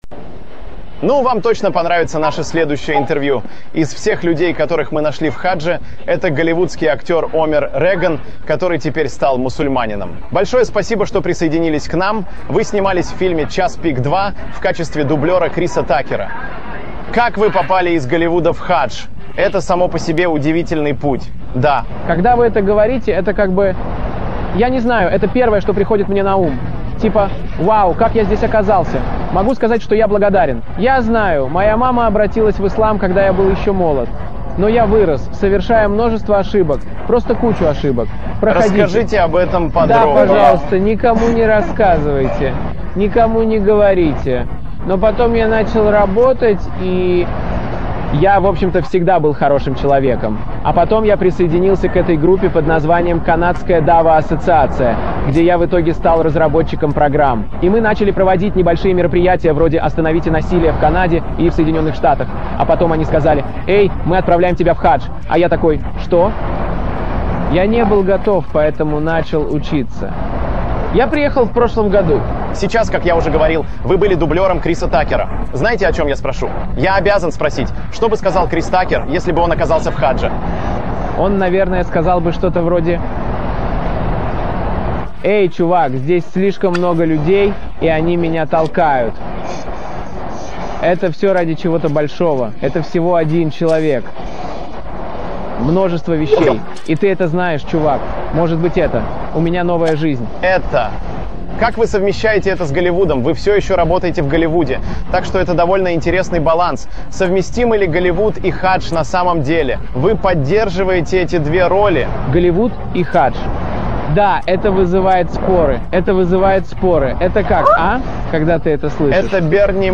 Описание: Омар Реган, голливудский актёр, даёт интервью международному телеканалу Al-Jazeera во время совершения пятого столпа ислама.